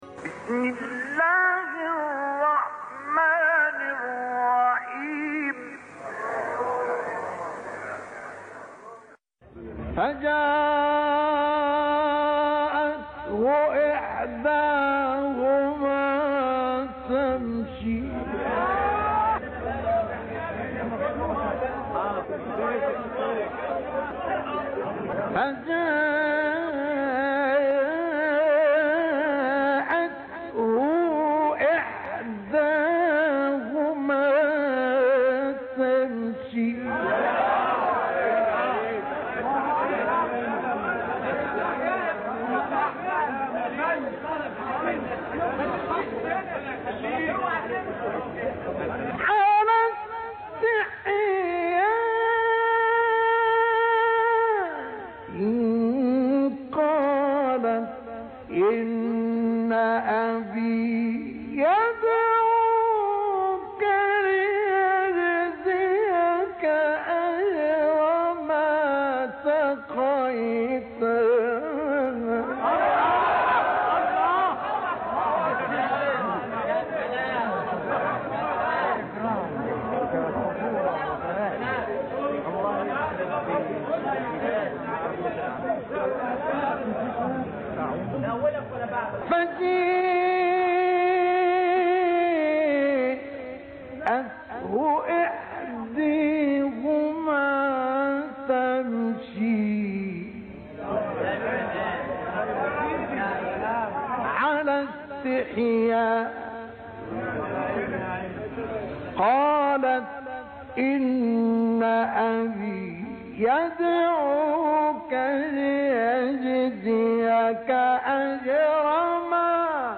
شاهکاری استاد مصطفی اسماعیل، آیاتی از سوره قصص